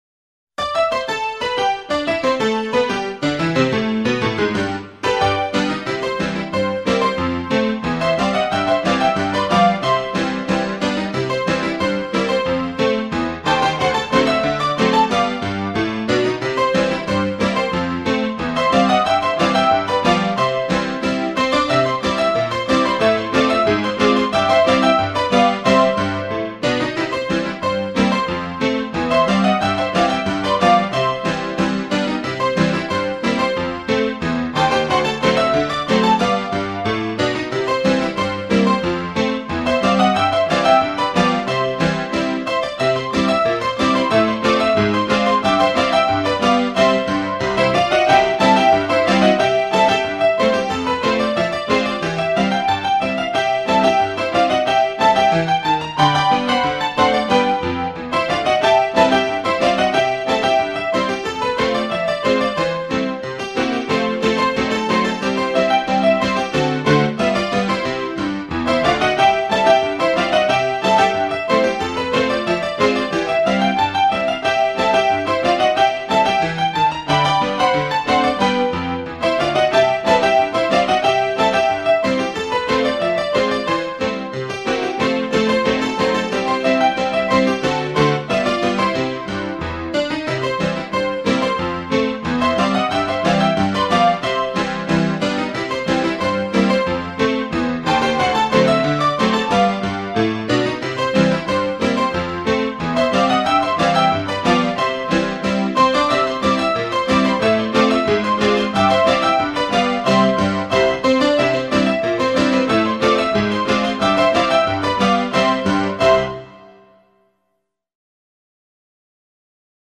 Регтайм - танцевальная музыка для детей - слушать онлайн
Широко известная танцевальная мелодия для веселых танцев взрослых и детей.
regtaym.mp3